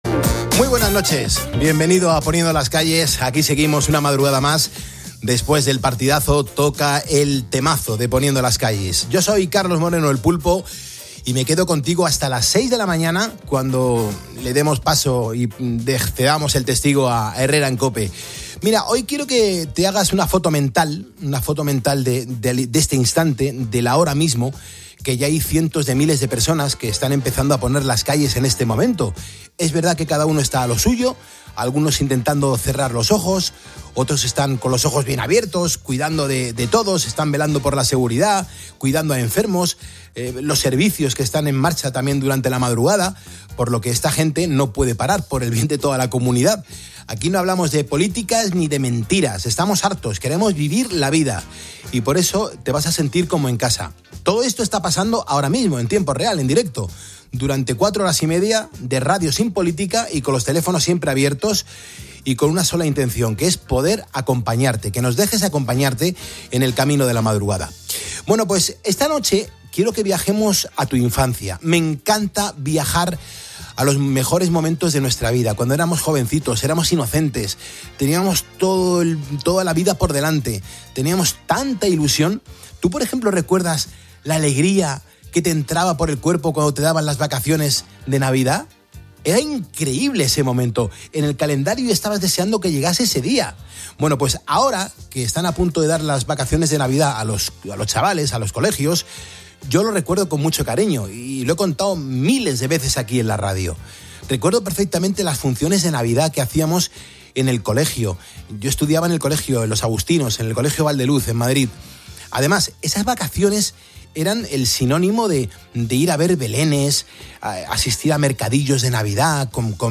madre de alumnos de una escuela rural